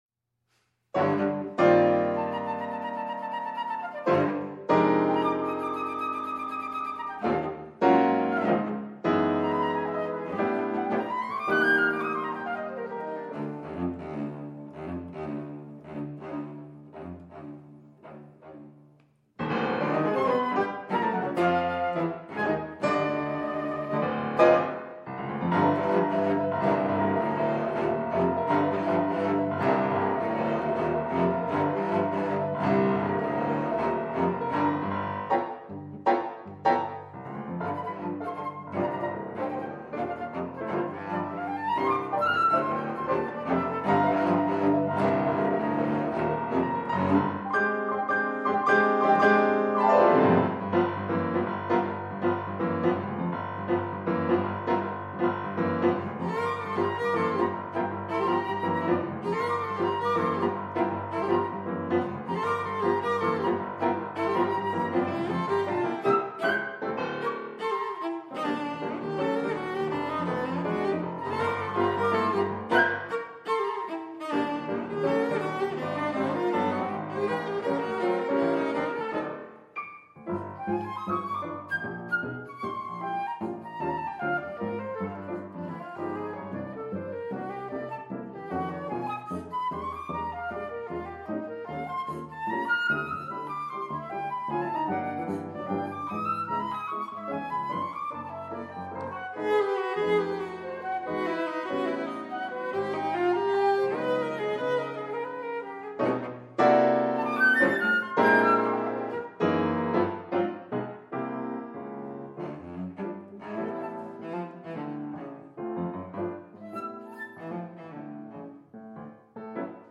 for Flute, Cello & Piano
I. Allegro molto